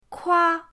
kua1.mp3